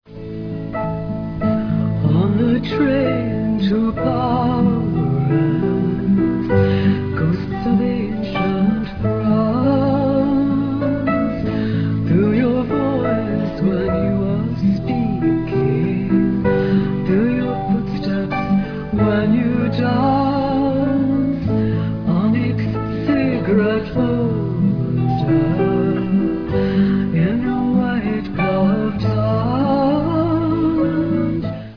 Real Audio/mono